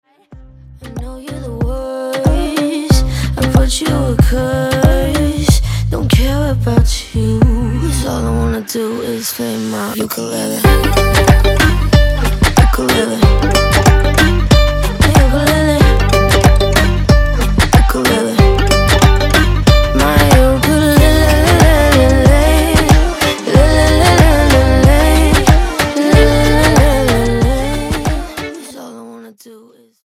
Genre : Blues.